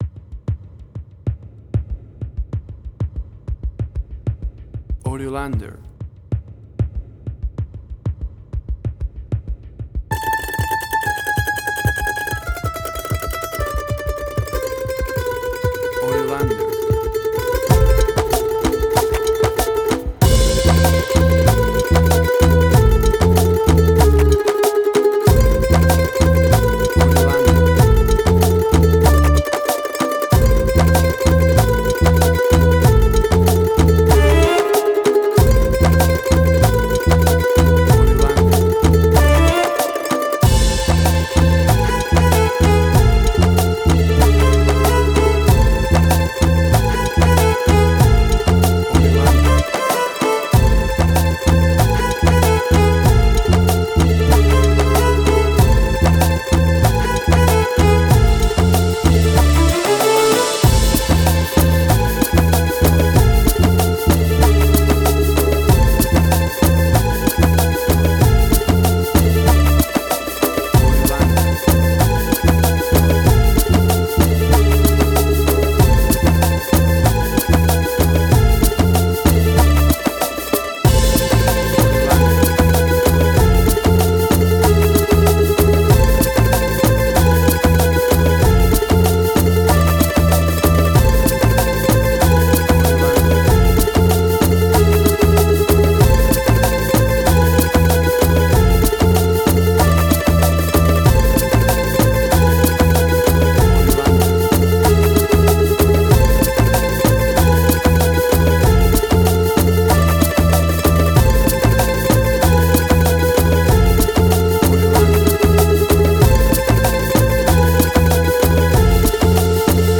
Tempo (BPM): 95